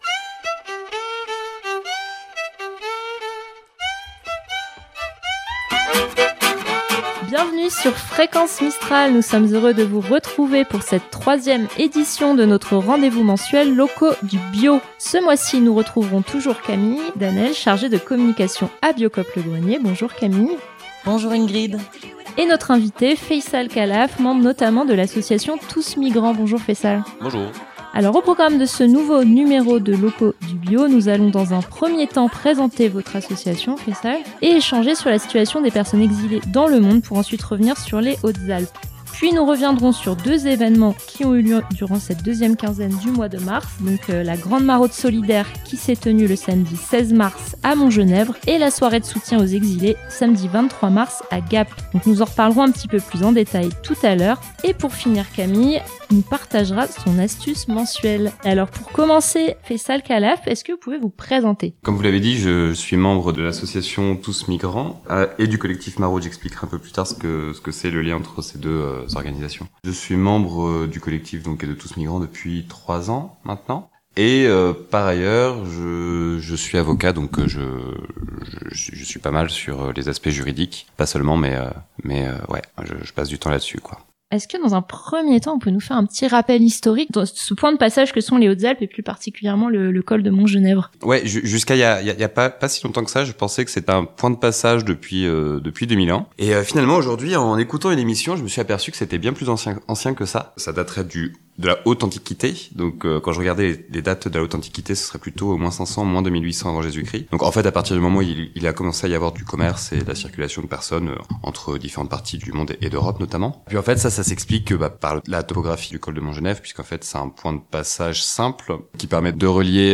Bienvenue dans l'émission Loco du Bio, notre rendez-vous mensuel en partenariat avec Biocoop Le Grenier, où l'on discute agriculture biologique, production locale, économie sociale et solidaire ou encore écologie. Chaque mois, nous y recevons des producteurs et productrices et des membres d'associations qui participent à la vie du territoire.